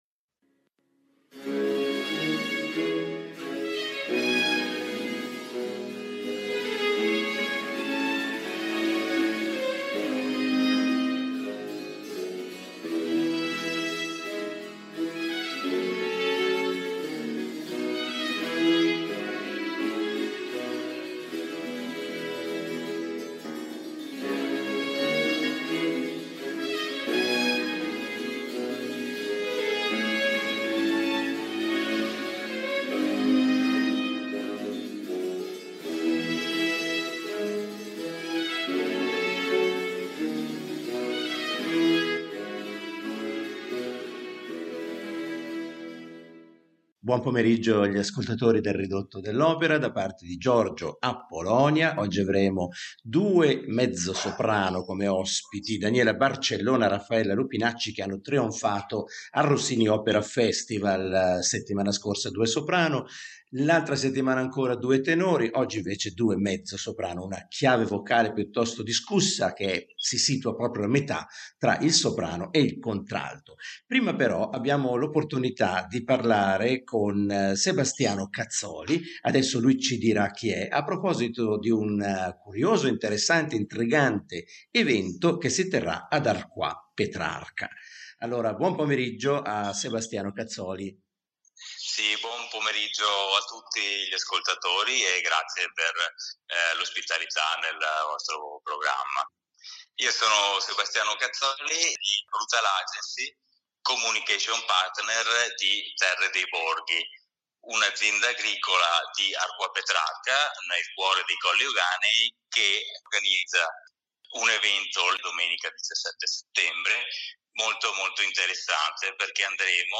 Per " Terra di Mezzo " giocosamente intendiamo il "mezzosoprano", ovvero una tipologia vocale femminile intermedia fra quella del soprano più acuta e quella del contralto più grave. Non utilizzato ai tempi di Gioachino Rossini, ha incominciato a trovare collocazione nel Romanticismo, da Donizetti in su.